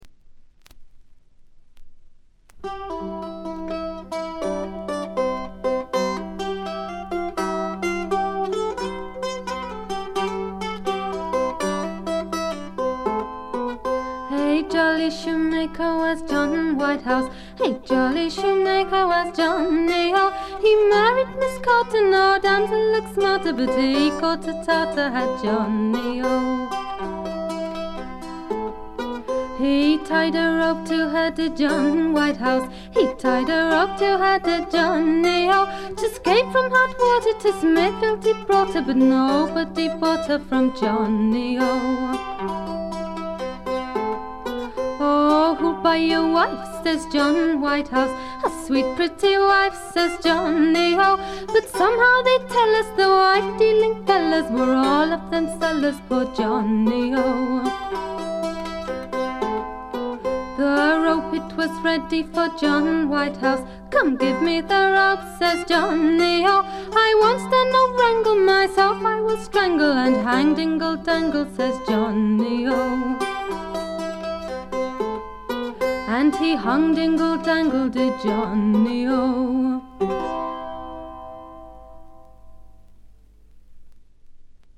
レア度、内容ともに絶品のフィメールトラッドフォークです。
試聴曲は現品からの取り込み音源です。